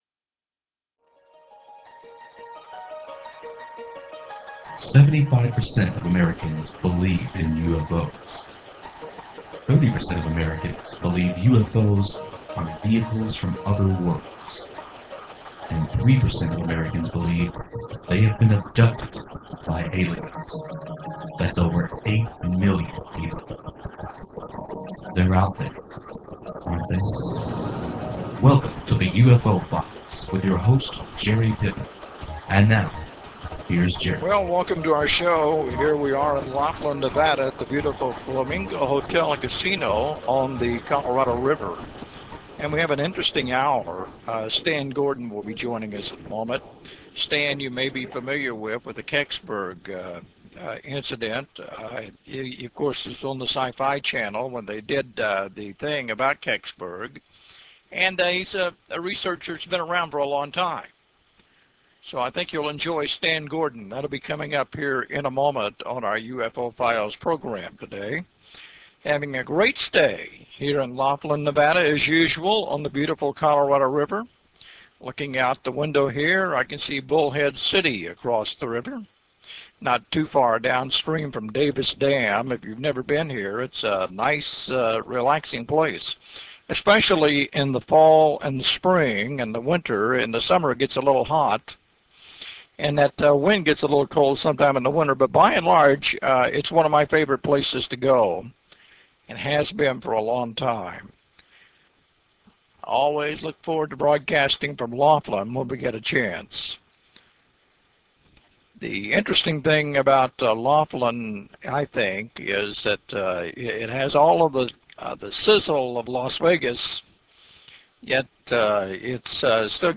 13th Annual International UFO Congress Convention and Film Festival Laughlin, Nevada February 8 - 14, 2004